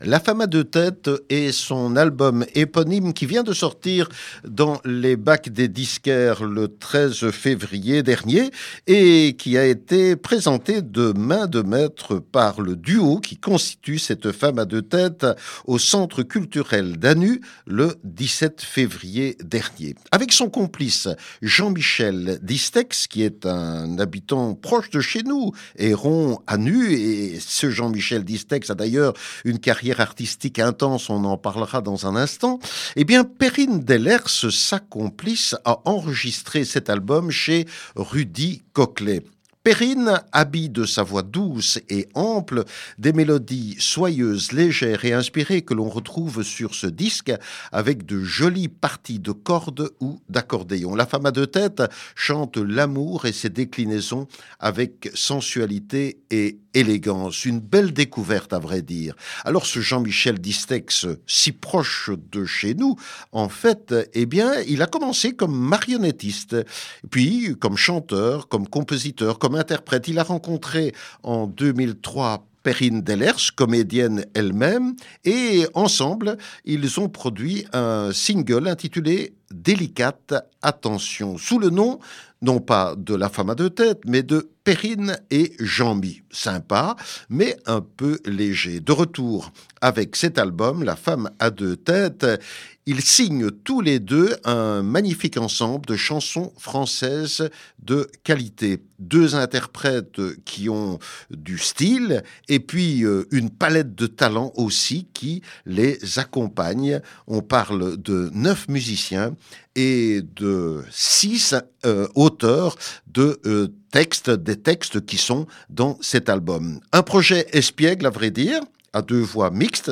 de chanson française qui existe depuis 2003.